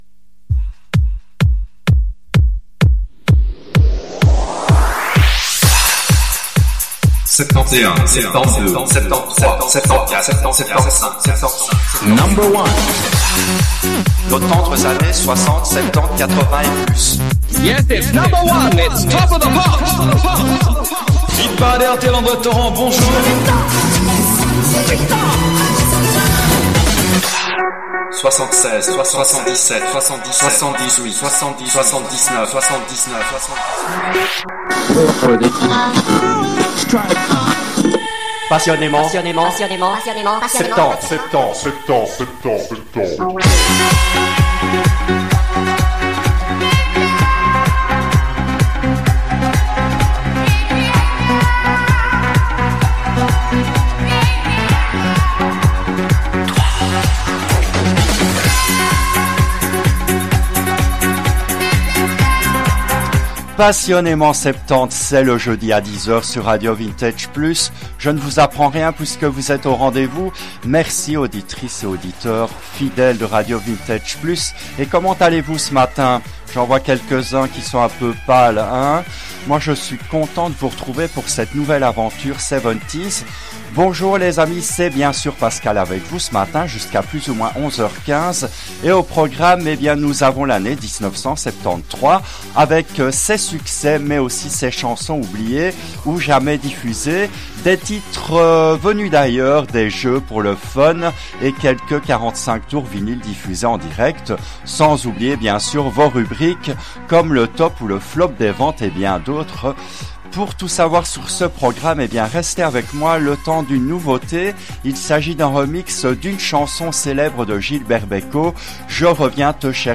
L’émission a été diffusée en direct le jeudi 15 janvier 2026 à 10h depuis les studios belges de RADIO RV+.